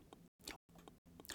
Please help me, I noticed that when I record audio clips I end up with mouth noises (pops, clicks, etc).
ps: I think it is worth saying that I place 2 pop filters on top of each other.
Hello friends, as per our discussion here is the audio clip with mouth noises (so sorry if it is annoying).